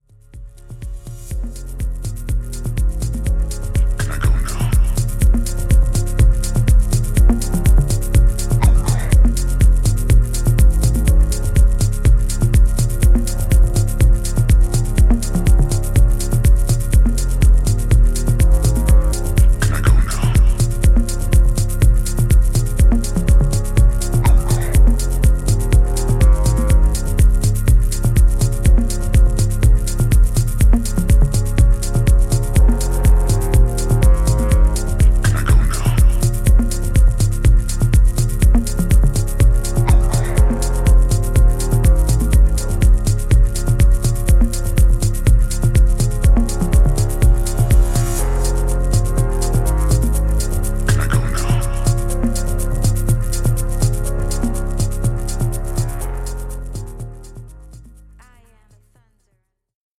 ホーム ｜ HOUSE / TECHNO > HOUSE